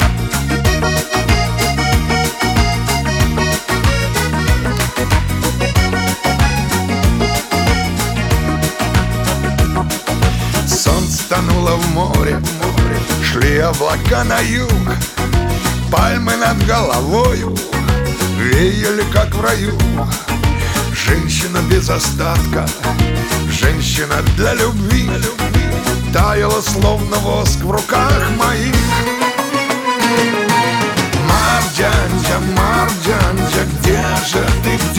Жанр: Русская поп-музыка / Русский рок / Русские
# Chanson in Russian